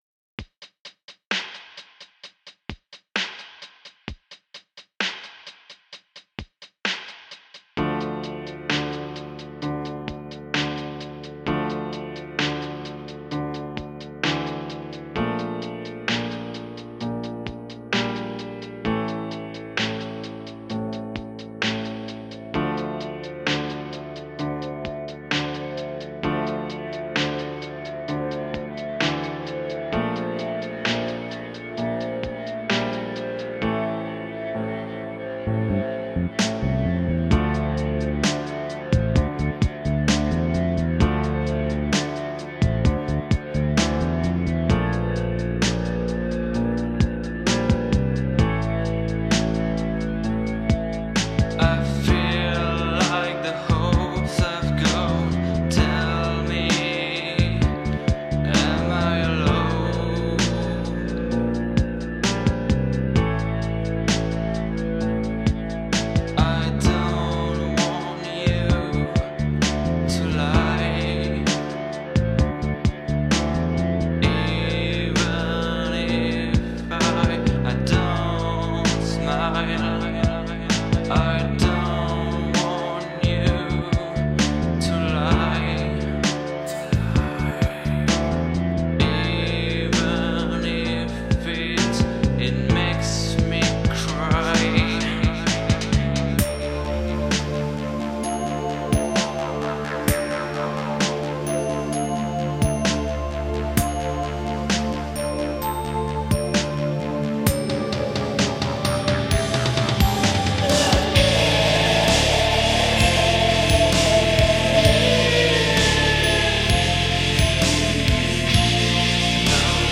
*фоновая музыка - Netra «Through the fear..»